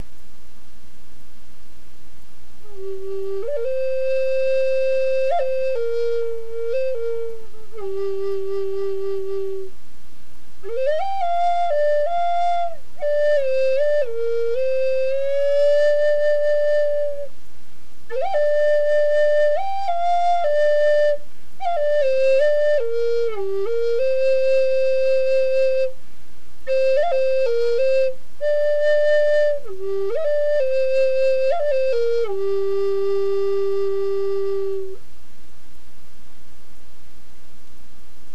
If you’re new to the Native American flute, Magic Wind Flutes recommends this basic Plains-style flute in the key of G as an excellent starter flute.
The tone from this flute, which is made from fine poplar orÂ aromatic cedar, is as rich as the wood we use to make it.